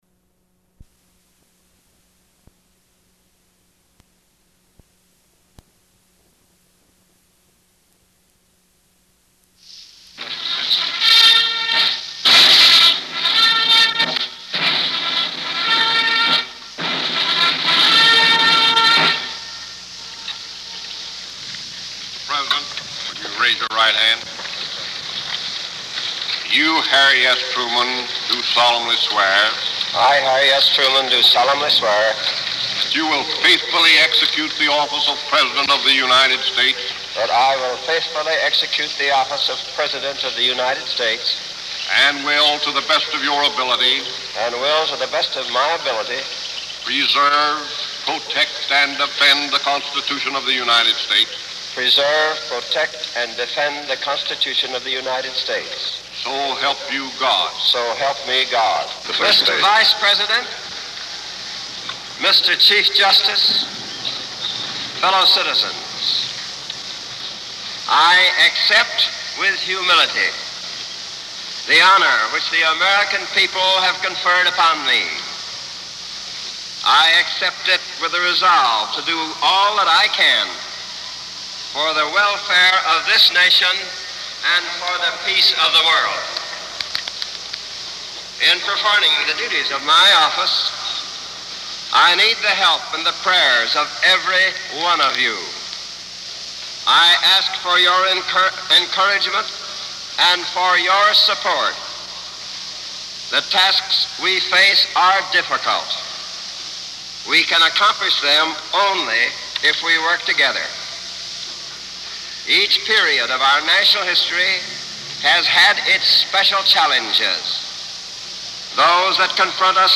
January 20, 1949: Inaugural Address | Miller Center
Presidential Speeches | Harry S. Truman Presidency